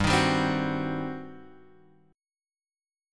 G Major 7th Suspended 4th Sharp 5th